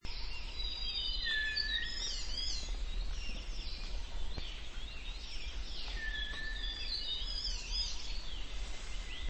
繡眼雀鶥 Alcippe morrisonia morrisonia
嘉義縣 阿里山 阿里山
錄音環境 森林
雄鳥歌聲前面有混其他種鳥聲
收音: 廠牌 Sennheiser 型號 ME 67